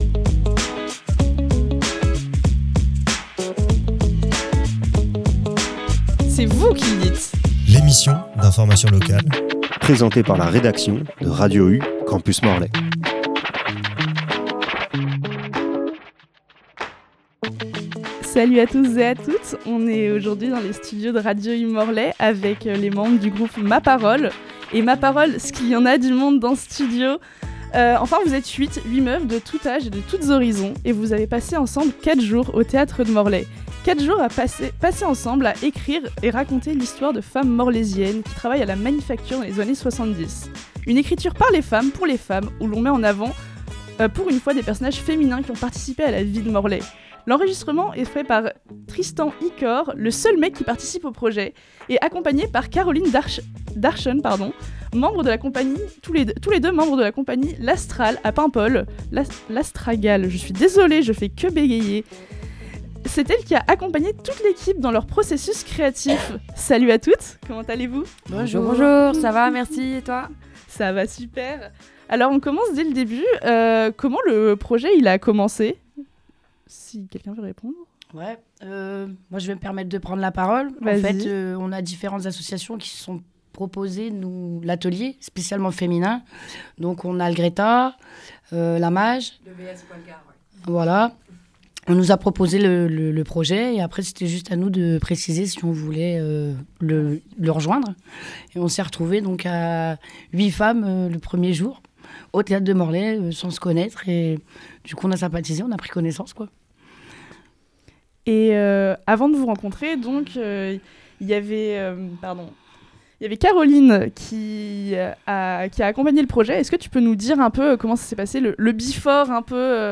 La création collective d’une pièce radiophonique